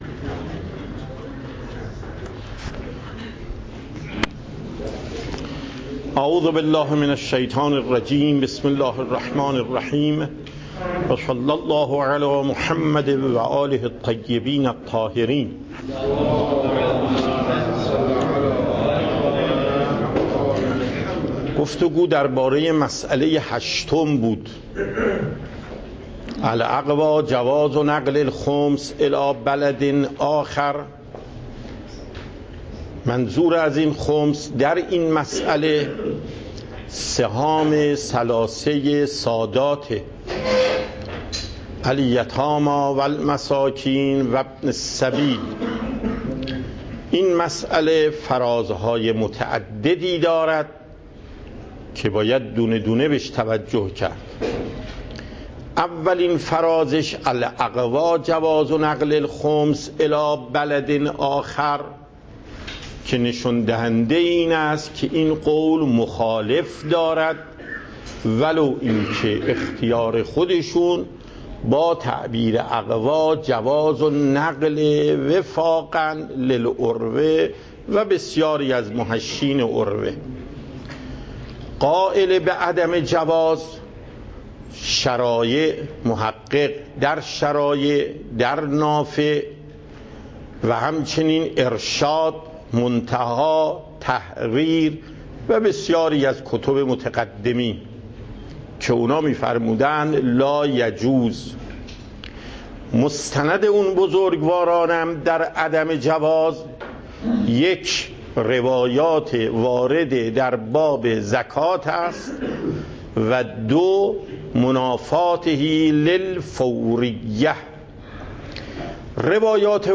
درس فقه آیت الله محقق داماد